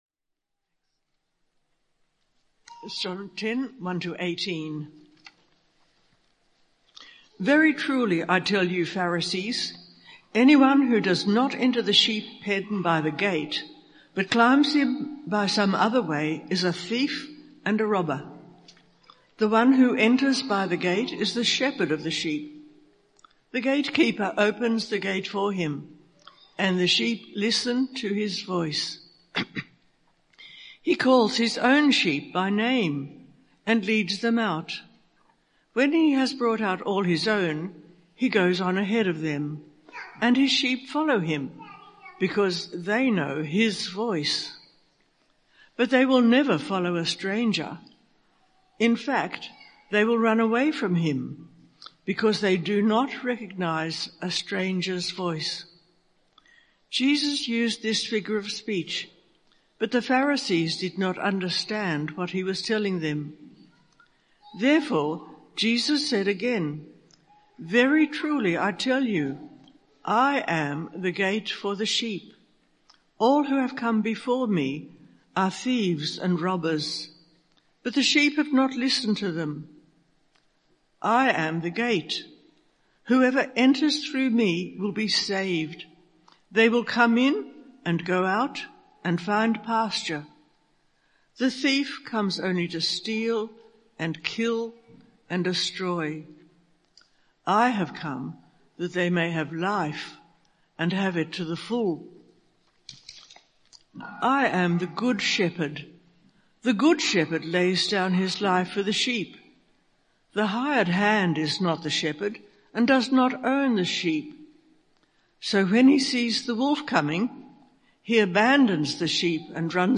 Talk Summary